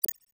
Holographic UI Sounds 81.wav